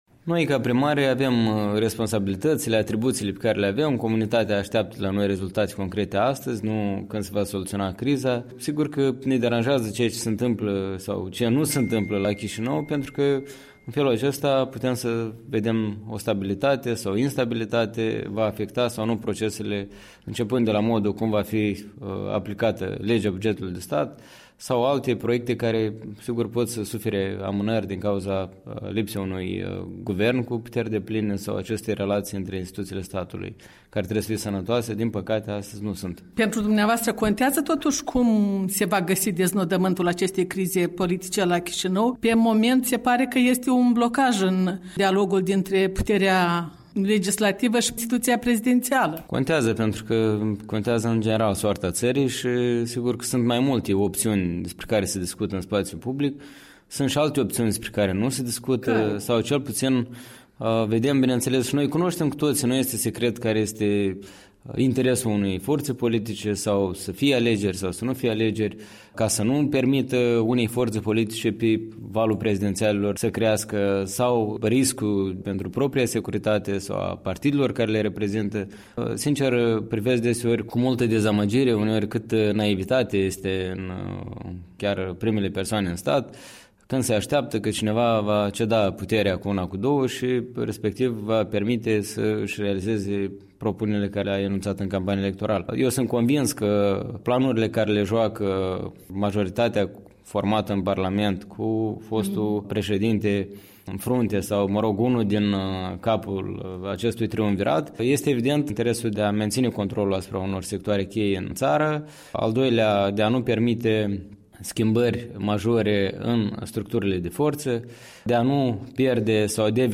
Interviu cu primarul municipiului Cahul despre problemele locale și despre cum criza politică îi afectează pe cei din teritoriu.